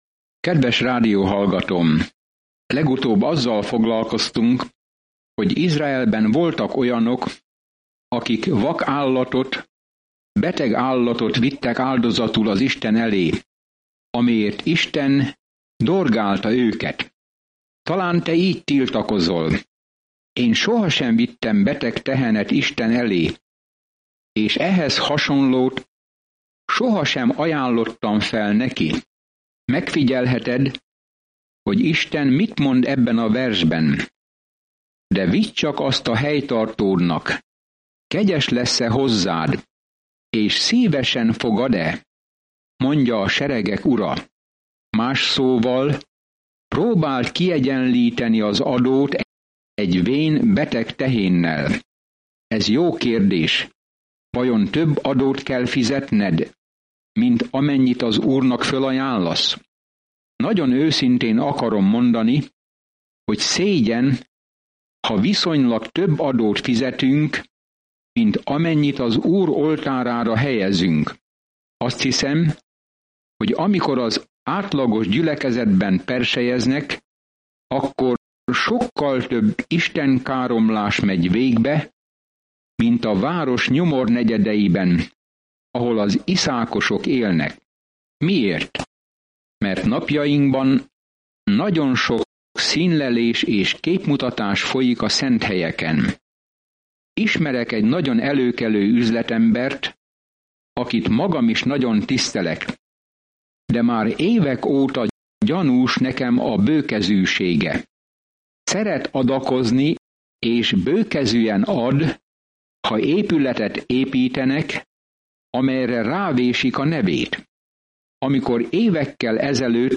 Szentírás Malakiás 1:9-13 Nap 2 Terv elkezdése Nap 4 A tervről Malakiás emlékezteti az elszakadt Izraelt, hogy üzenete van Istentől, mielőtt hosszú csendet tűrnének ki – aminek akkor lesz vége, amikor Jézus Krisztus színre lép. Napi utazás Malakiáson keresztül, miközben hallgatod a hangos tanulmányt, és olvasol válogatott verseket Isten szavából.